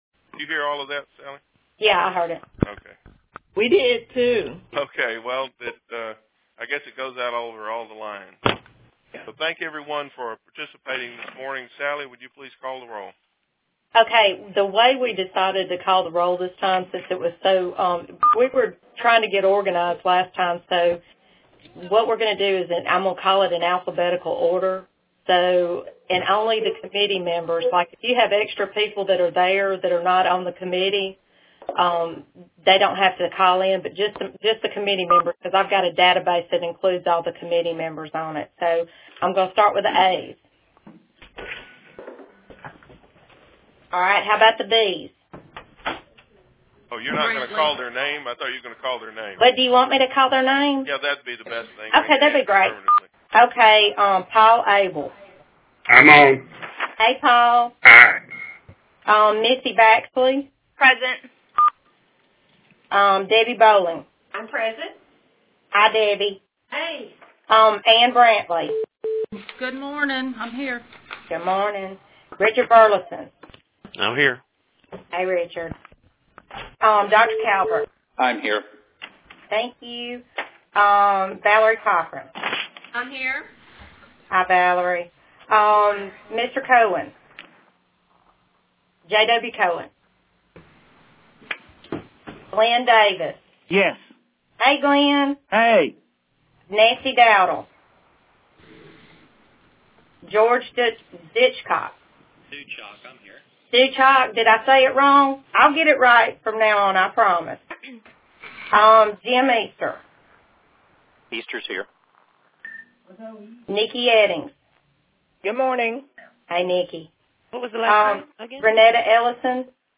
Alabama Department of Public Health: Pandemic Influenza Working Group Conference Call Recording
HealthSectorCall2_28_07.mp3